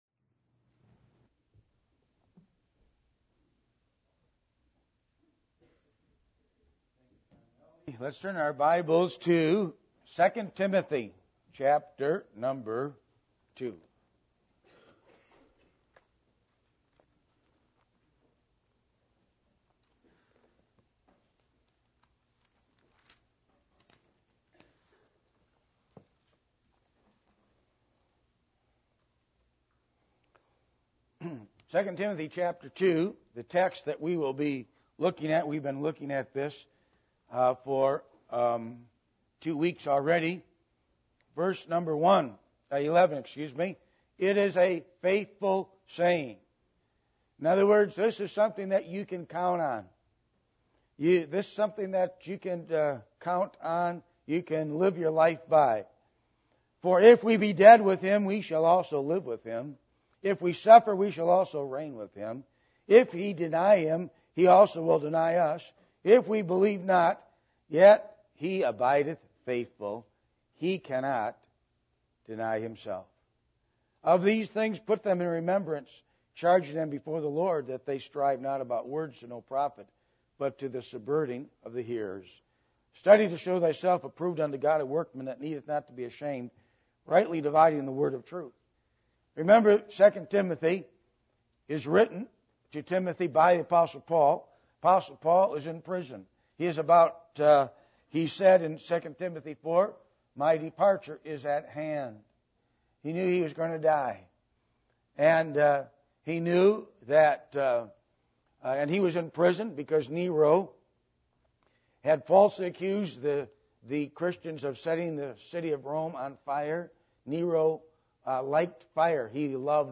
Passage: 2 Timothy 2:11-15 Service Type: Sunday Morning